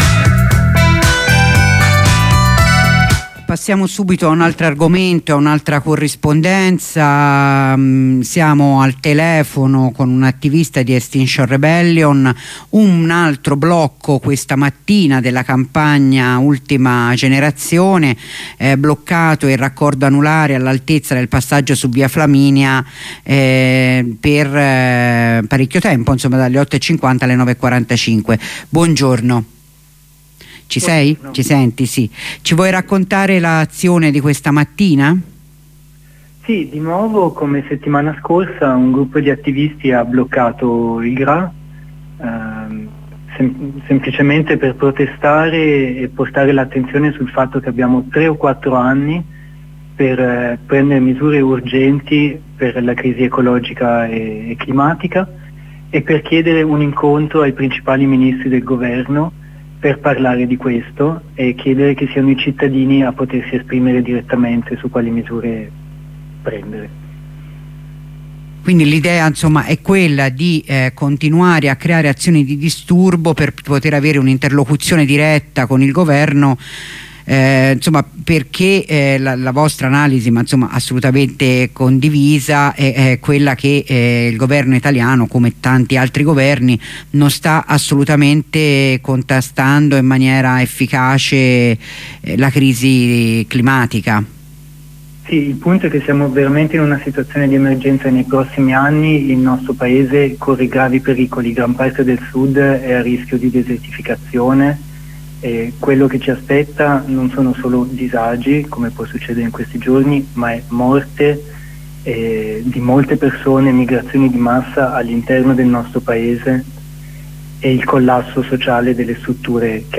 Corrispondenza con un attivista di Extinction Rebellion